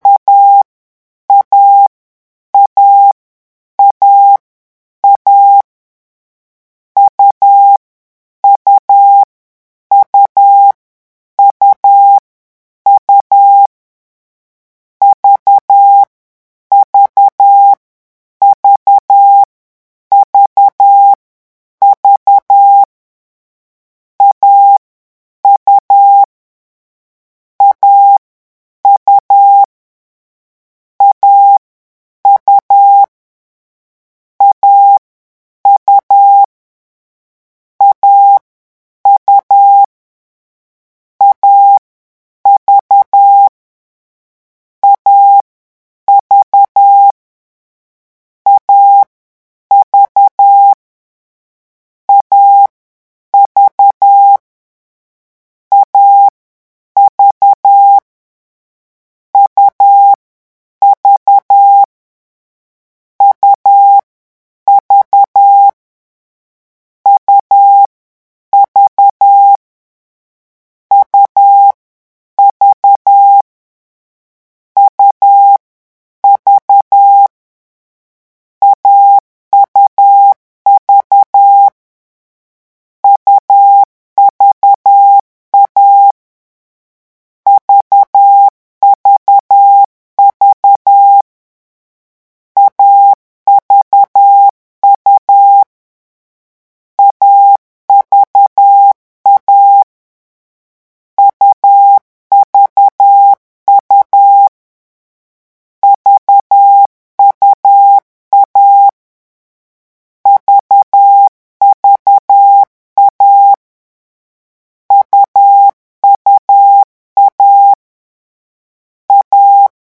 A U V
vitesse de 10 mots minute :
Leçon 3 - vitesse 10 mots minute
lecon3-vitesse_10.mp3